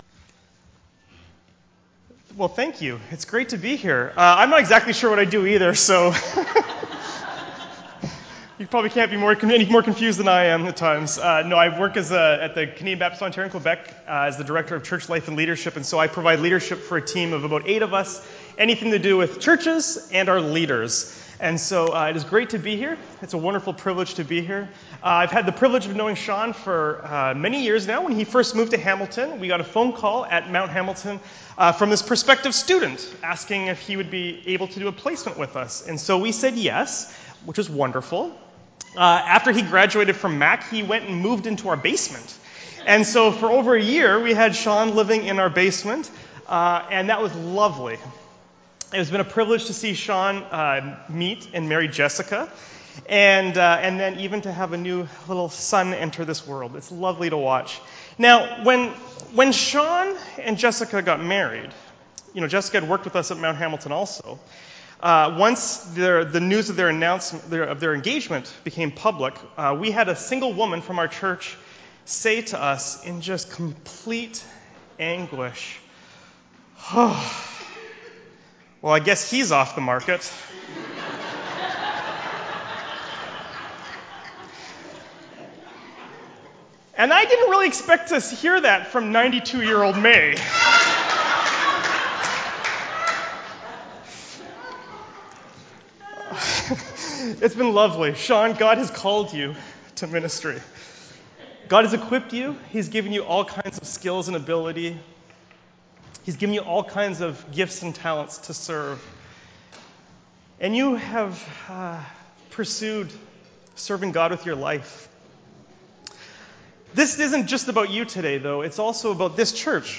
Ordination Service